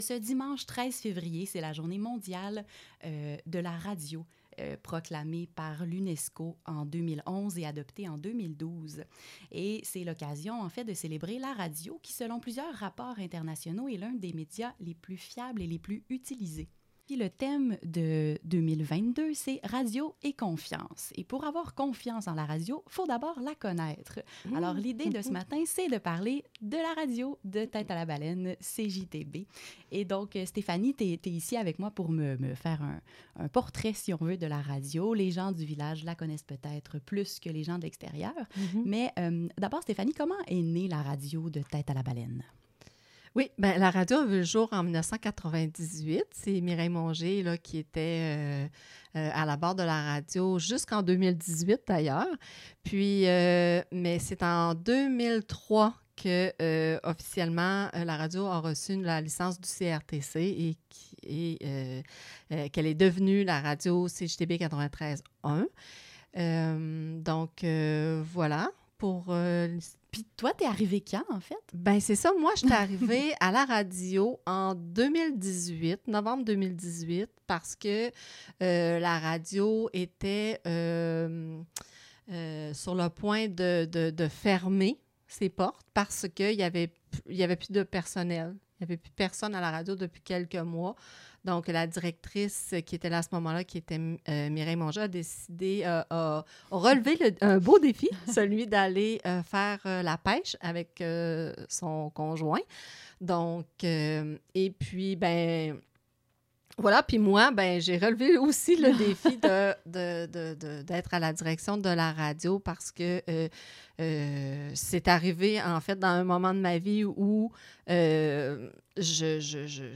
Jour-radio-entrevue-complete.mp3